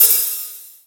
OP HI-HAT 1.wav